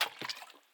water-splash.mp3